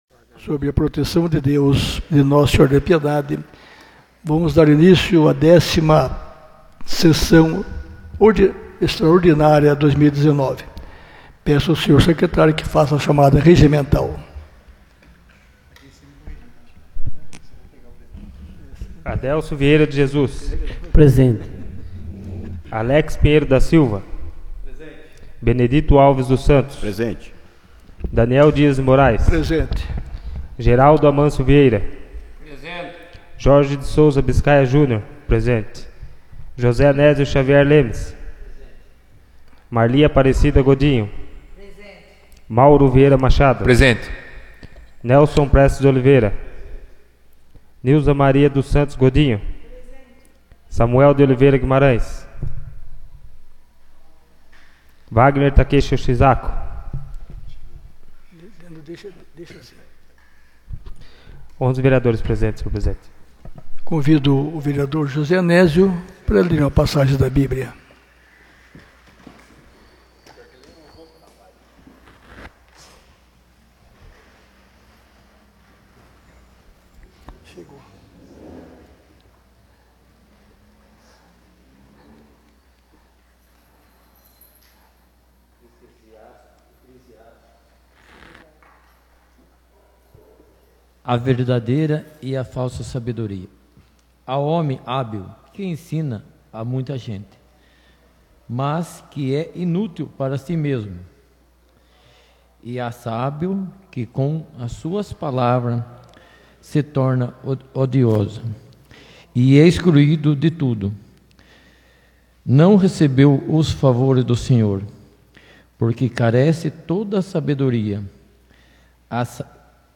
10ª Sessão Extraordinária de 2019